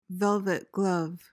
PRONUNCIATION:
(VEL-vet gluhv)